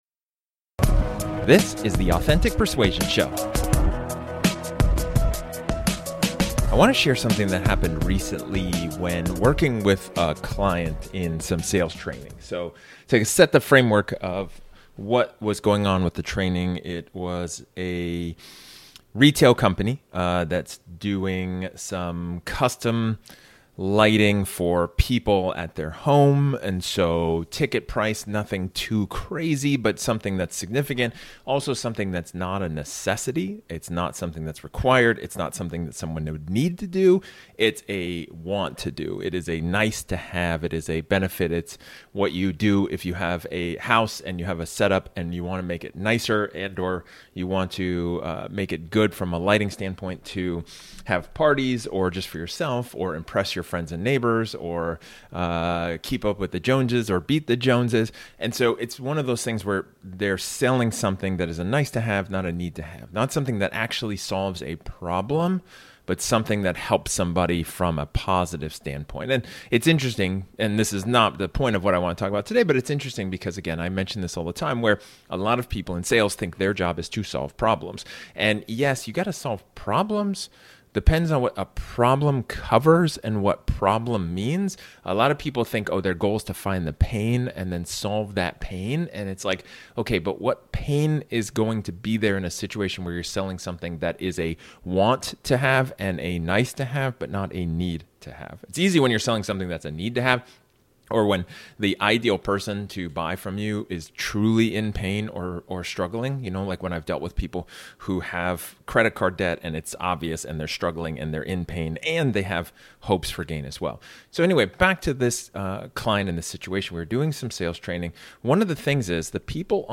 In this solo episode, I dive into the world of sales training and explore an interesting scenario, and where I share a recent experience working with a client in the retail industry.